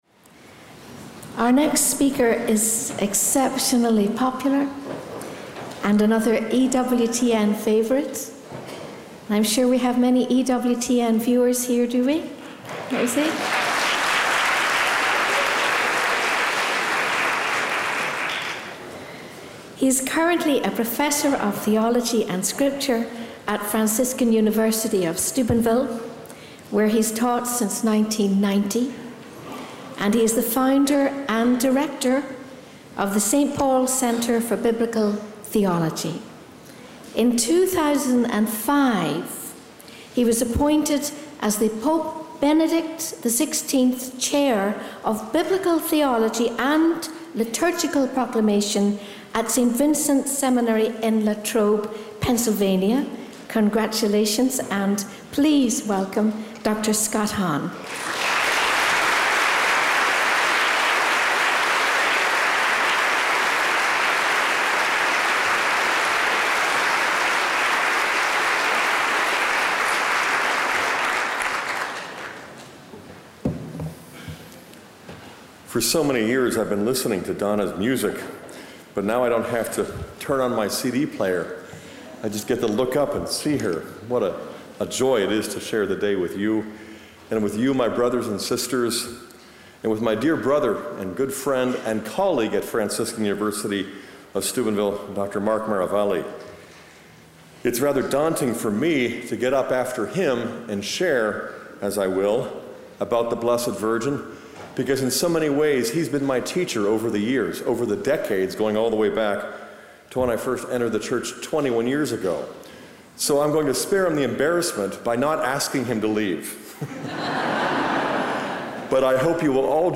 At the Behold Your Mother Marian Conference at Our Lady of the Greenwood in Indiana in 2007